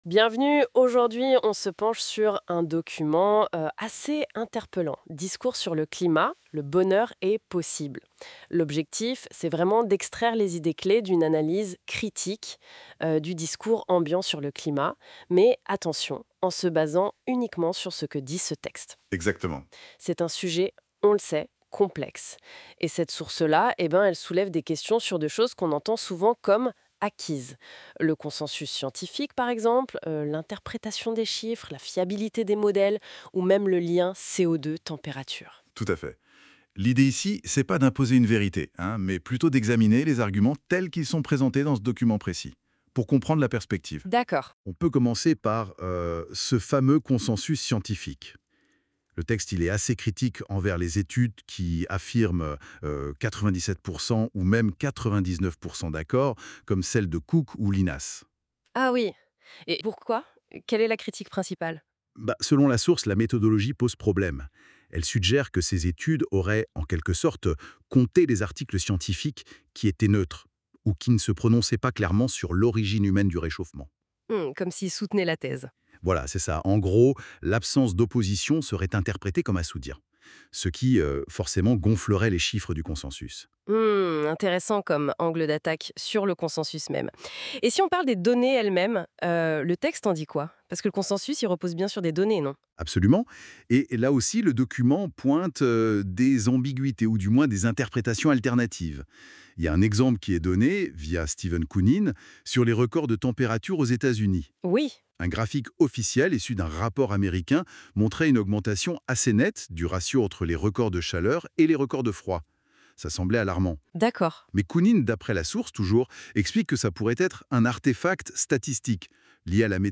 Pour un aperçu synthétique du dossier, consulter un résumé , huit questions sur le climat , la chronologie du débat climatique , ou écouter une présentation dialoguée de ce dossier.